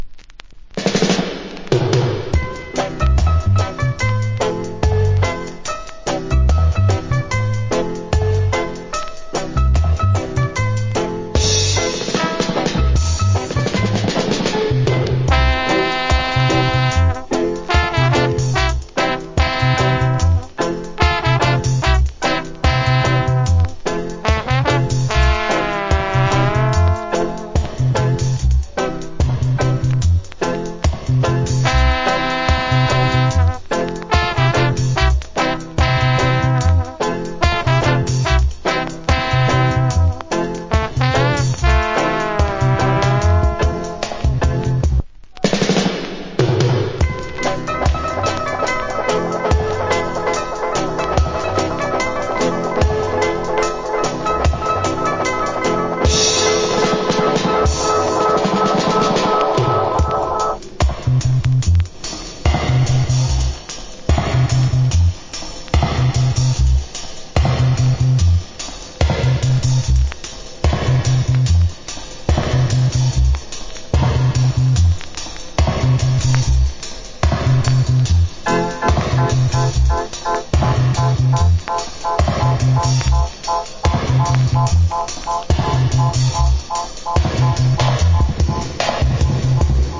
Cool Trombone.